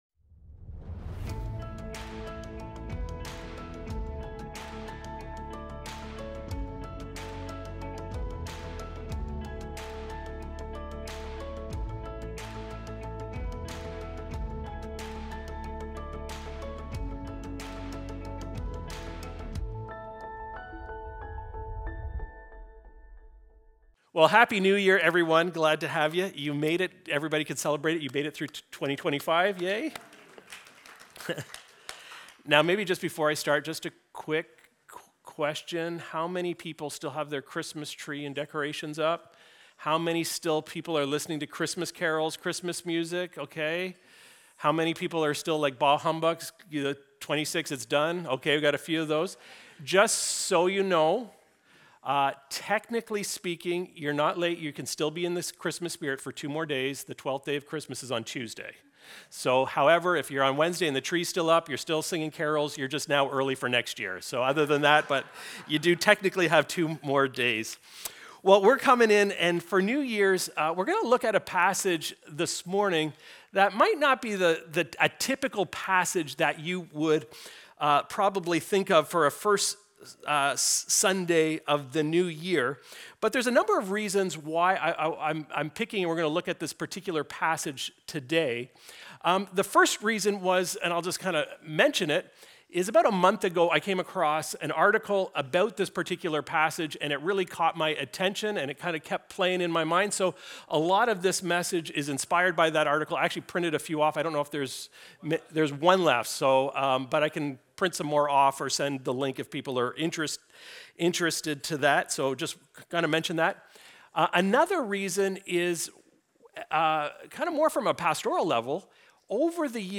Recorded Sunday, January 4, 2026, at Trentside Bobcaygeon.